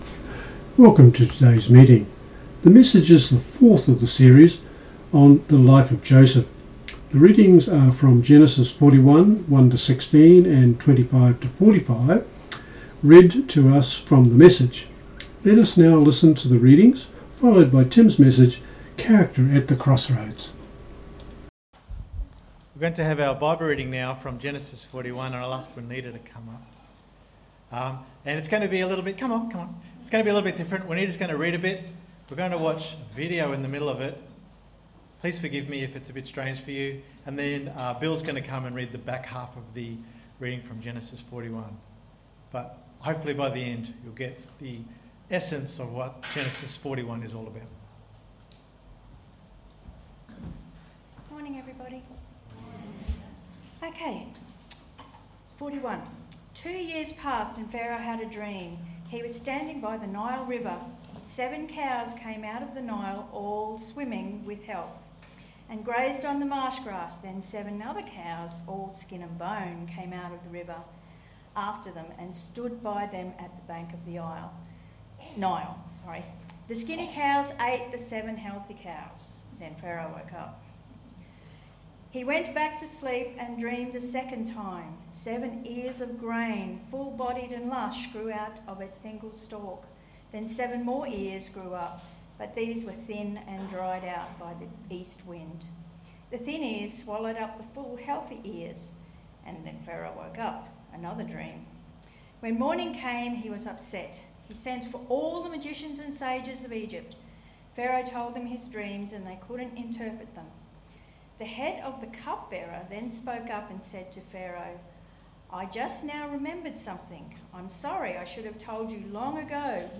The readings are from Genesis 41:1-16 and 25-45 read to us from the MSG.